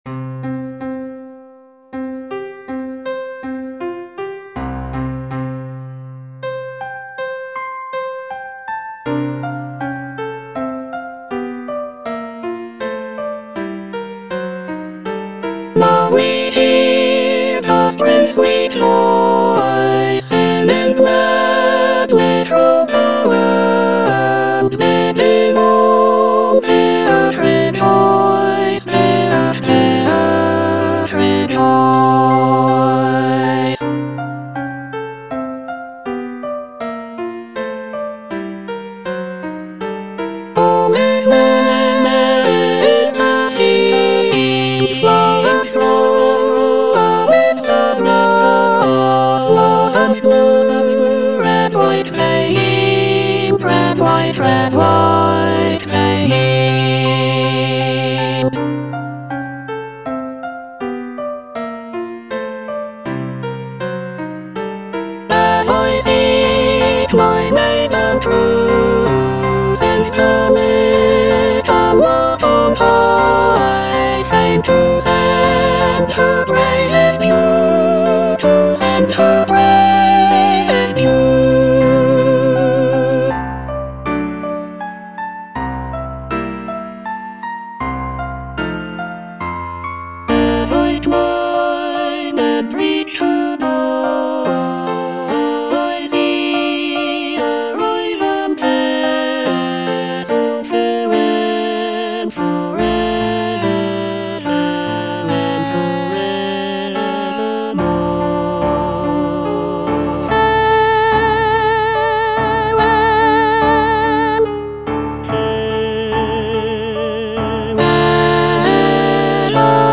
E. ELGAR - FROM THE BAVARIAN HIGHLANDS False love - Soprano (Emphasised voice and other voices) Ads stop: auto-stop
The piece consists of six choral songs, each inspired by Elgar's travels in the Bavarian region of Germany. The music captures the essence of the picturesque landscapes and folk traditions of the area, with lively melodies and lush harmonies.